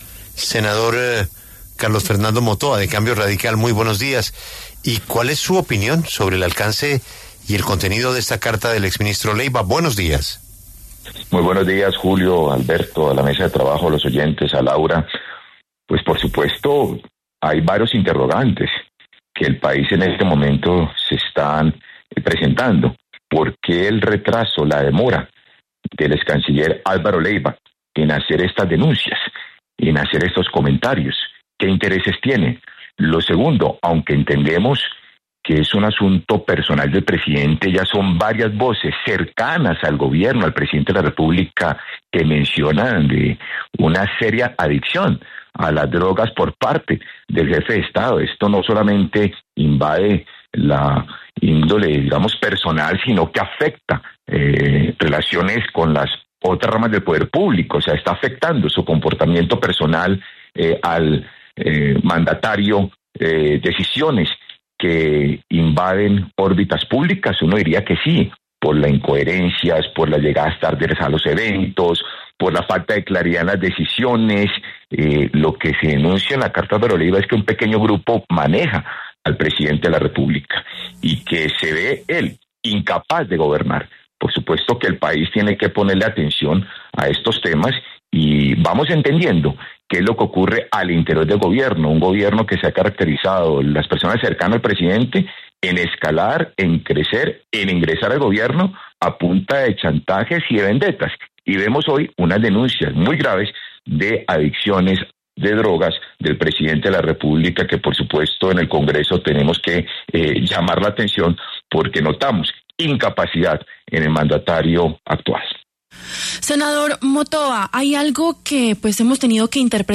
Desde Cambio Radical, el senador Carlos Fernando Motoa habló en La W. Dijo que el comportamiento personal del presidente está afectando decisiones públicas.